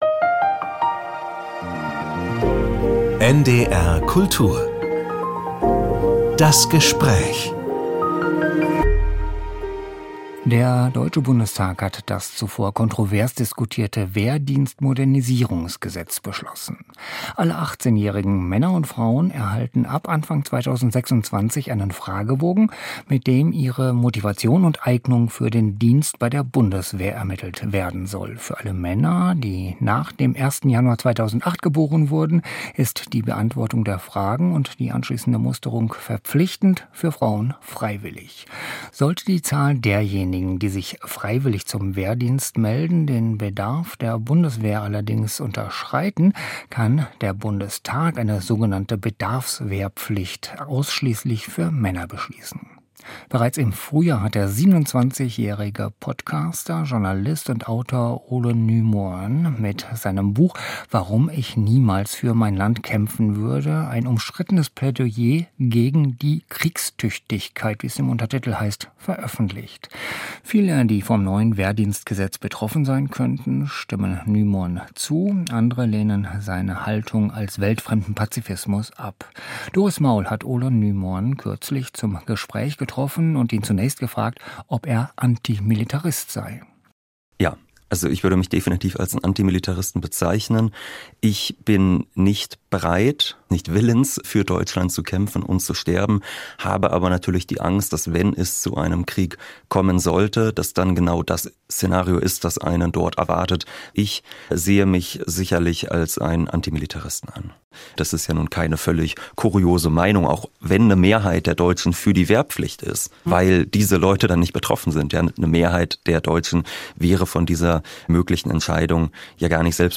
Gespräch ~ NDR Kultur - Das Gespräch